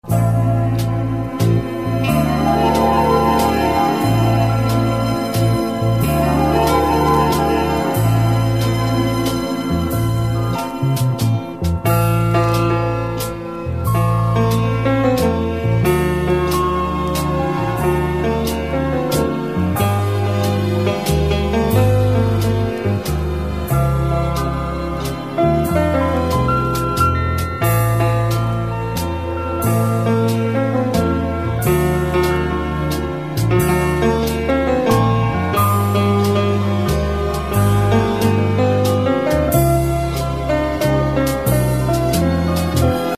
• Качество: 128, Stereo
красивые
спокойные
без слов
пианино